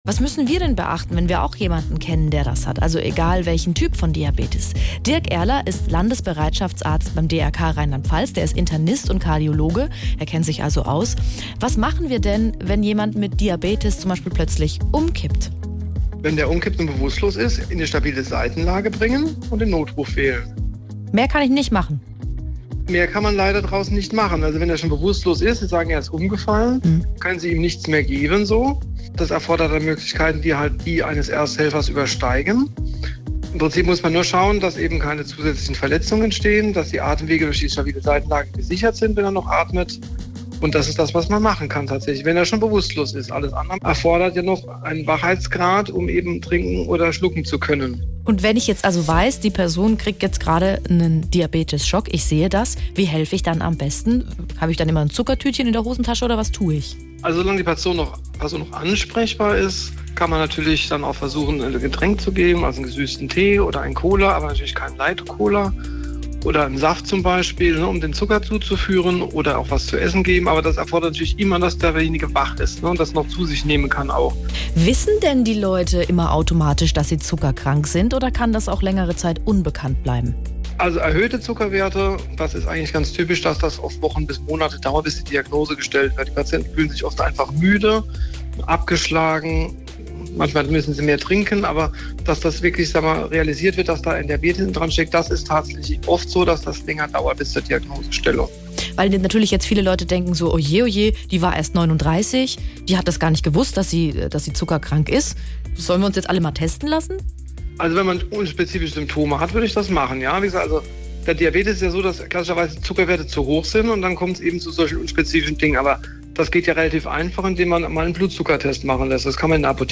Das ganze Interview hört ihr hier – auch Antworten darauf, welche Symptome auf Diabetes hinweisen können und wie man sich testen lassen kann: